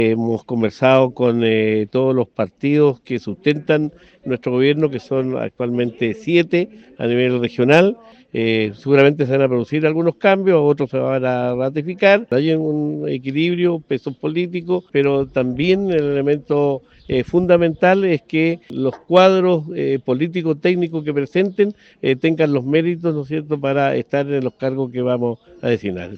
Consultado por Radio Bío Bío, Cuvertino confirmó diálogos con los representantes de partidos del oficialismo, para evaluar la llegada de nuevos nombres, por ejemplo, a algunas jefaturas de división del Gobierno Regional.